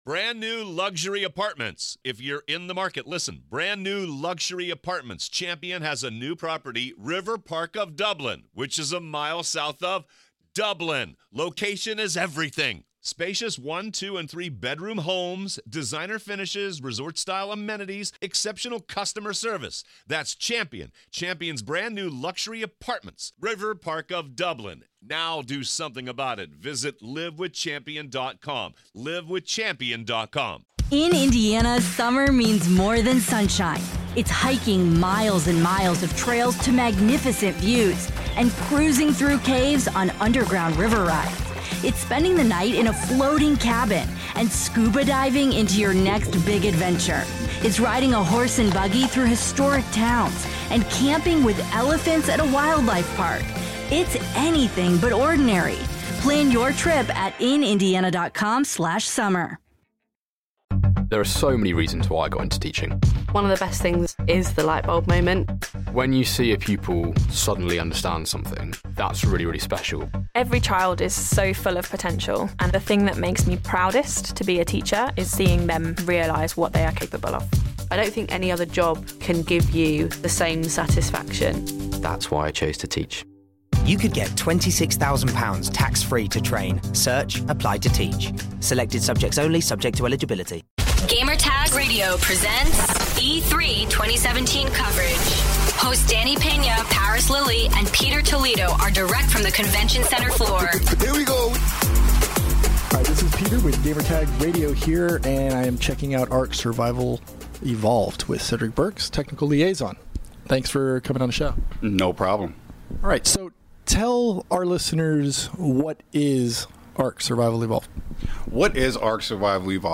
E3 2017: Ark Survival Evolved Interview